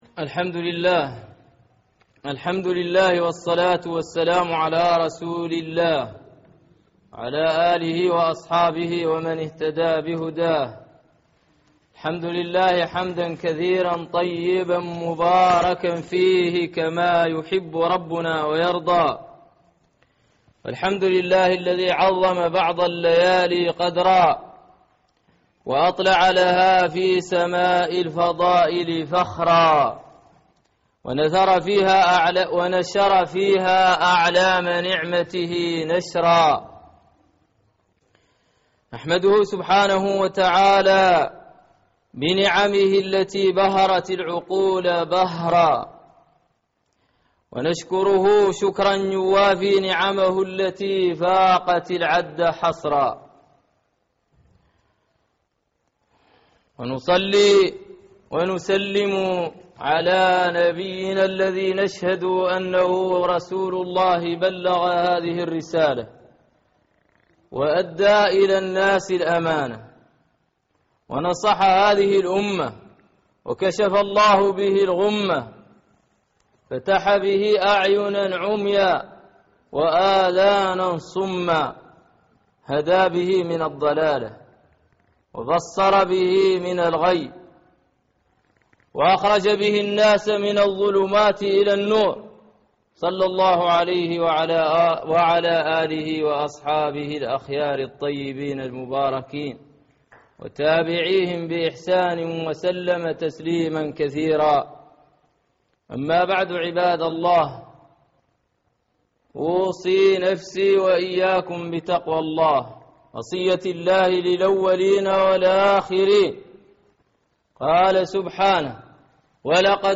خطب | بصائر